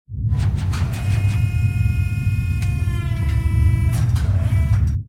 repair3.ogg